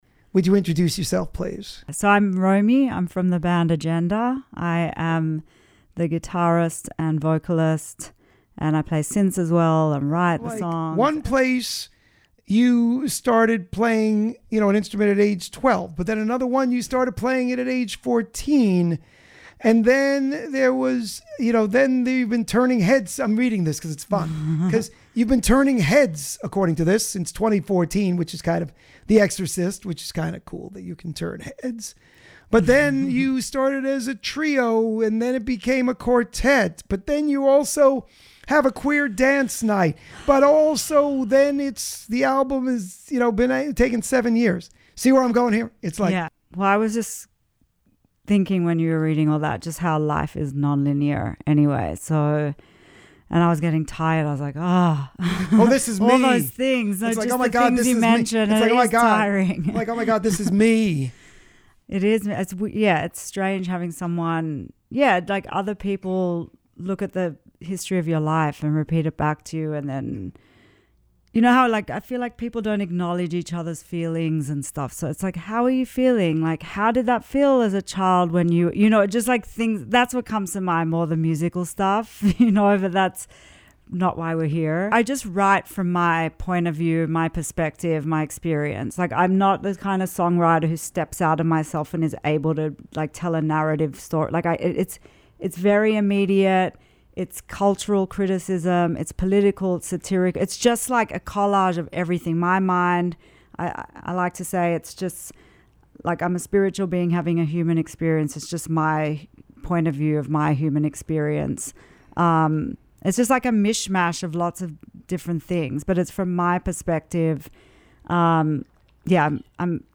This Week's Interview (06/26/2022): Agender